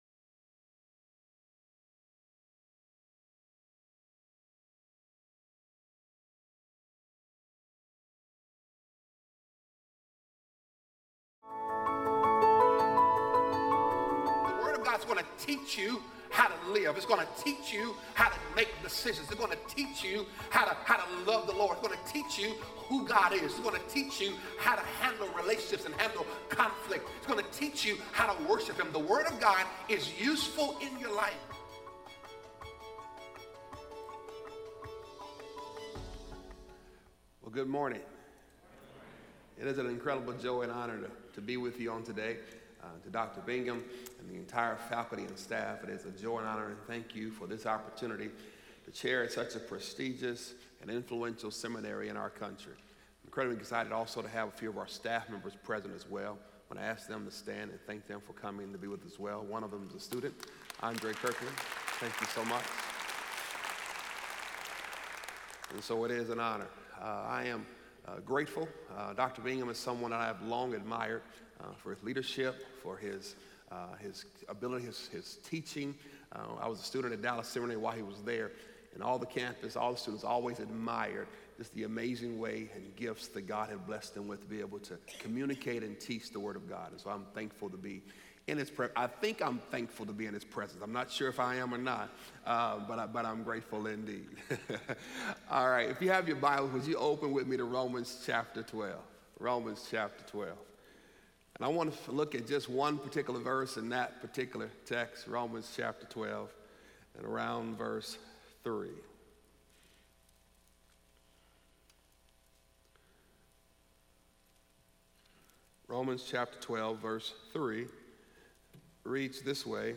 speaking on Romans 12:3 in SWBTS Chapel on Thursday September 13, 2018